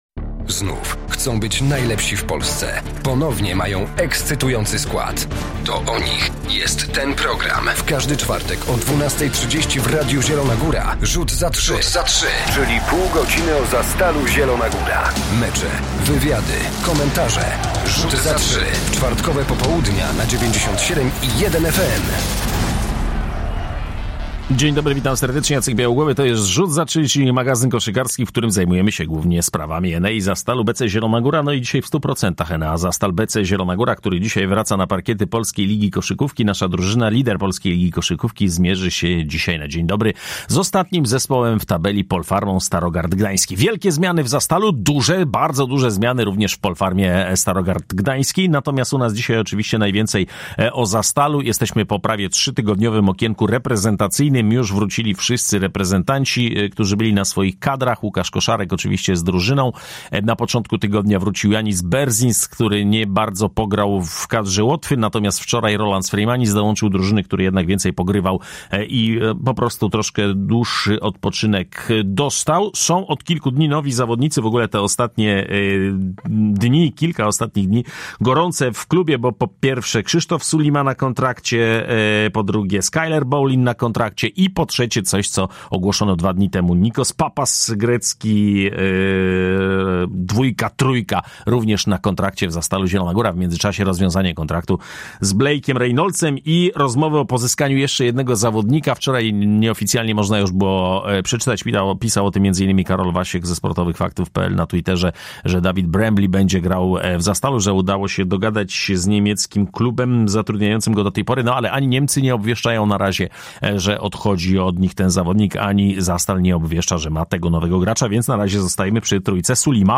Zapraszamy na cotygodniowy magazyn koszykarski Rzut za trzy. Dziś bardzo dużo o nowym składzie Zastalu, który już wieczorem zagra po przebudowie w PLK z Polpharmą Starogard Gdański.